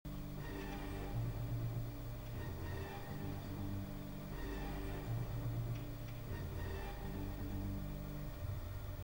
a 9 second clip where u can barely hear anything?